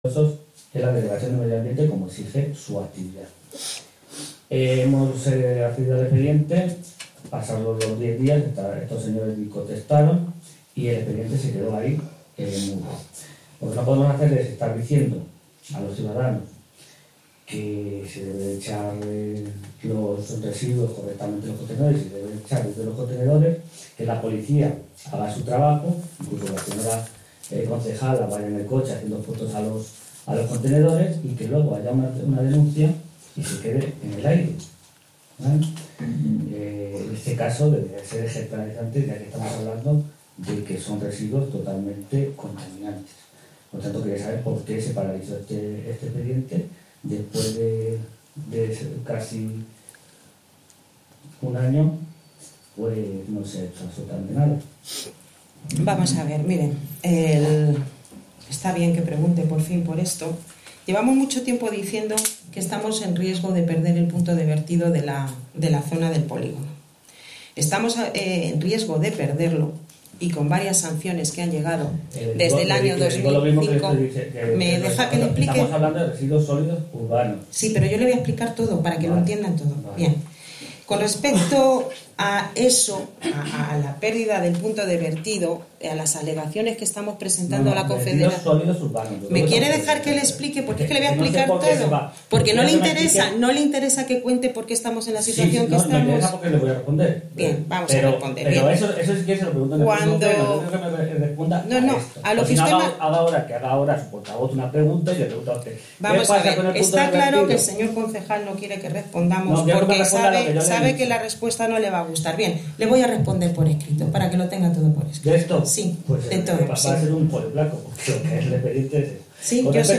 Audio del pleno ordinario de 4 de septiembre de 2017.